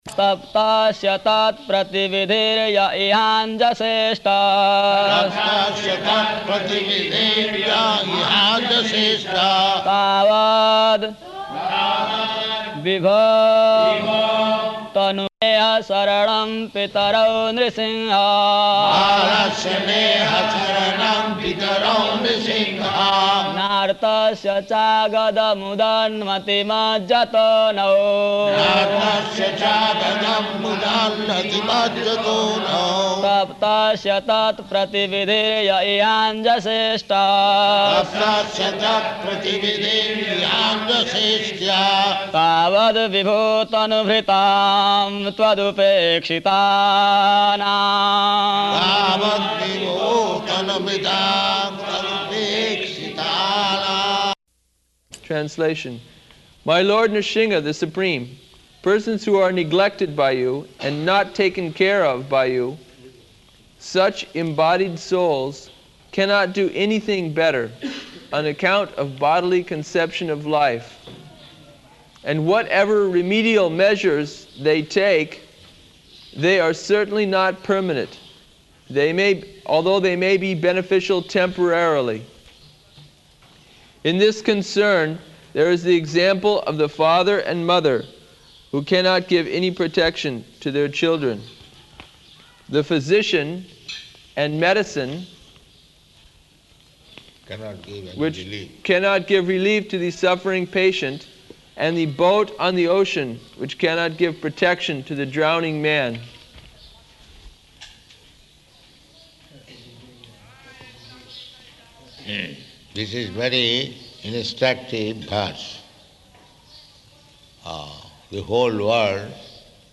Location: Māyāpur
[chants verse; Prabhupāda and devotees chant responsively]